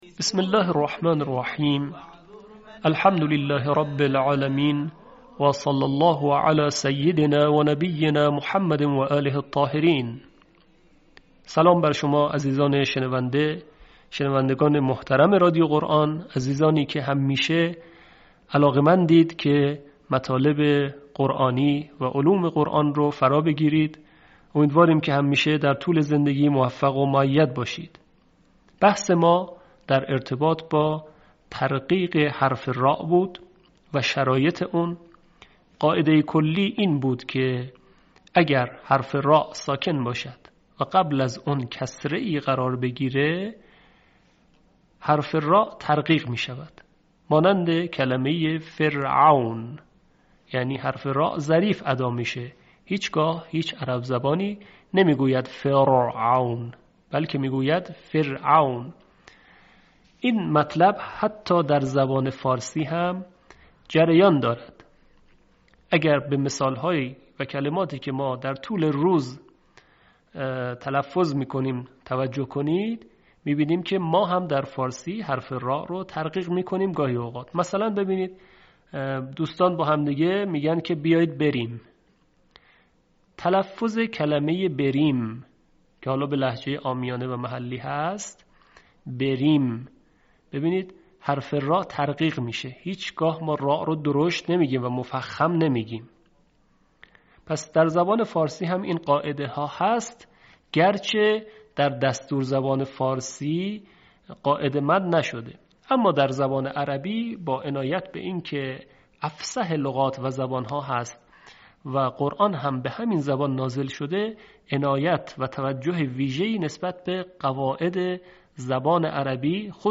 یکی از مهم‌ترین سیاست‌های ایکنا نشر مبانی آموزشی و ارتقای سطح دانش قرائت قرآن مخاطبان گرامی است. به همین منظور مجموعه آموزشی شنیداری (صوتی) قرآنی را گردآوری و برای علاقه‌مندان بازنشر می‌کند.